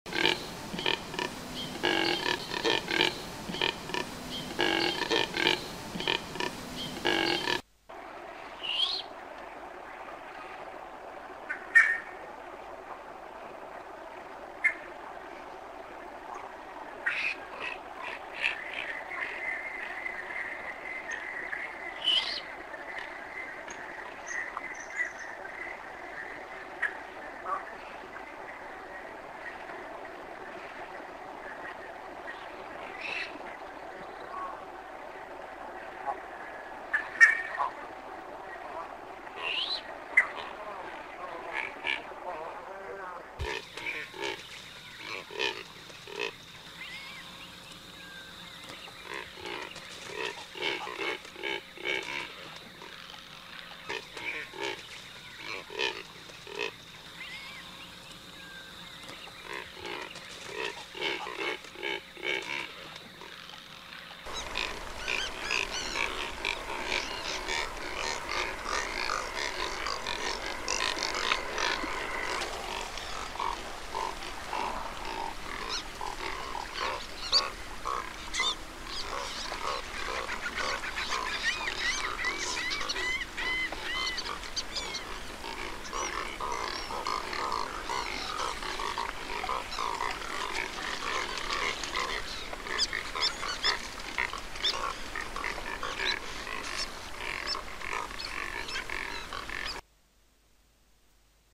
BIGUÁ
Bigua-Cantando.mp3